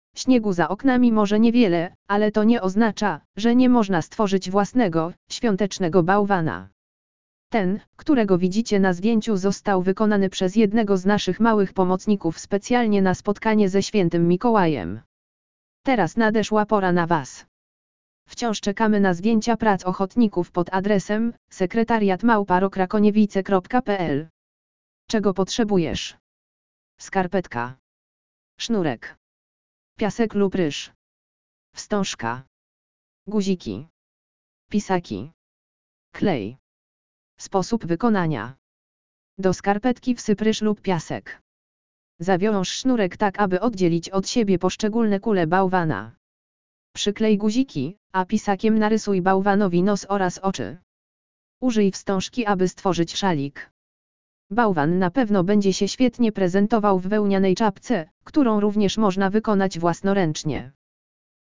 audio_lektor_skarpetkowe_balwanki.mp3